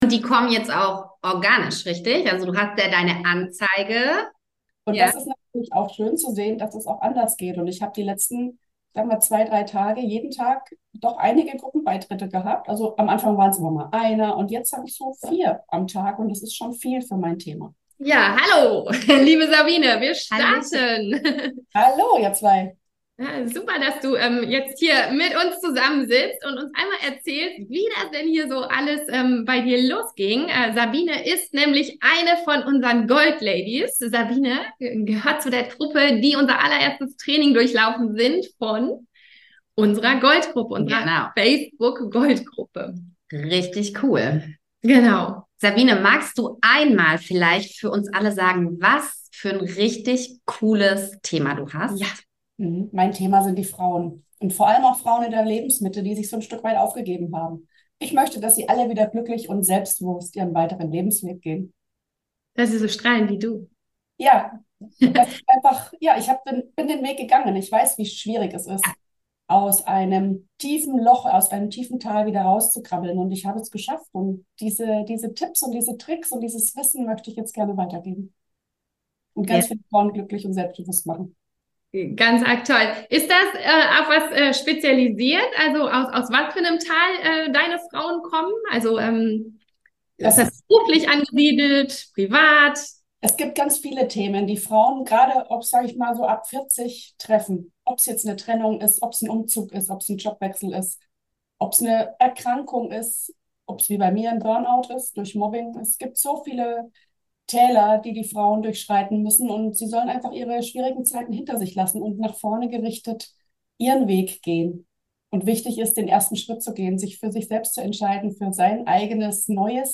Erfolgsinterview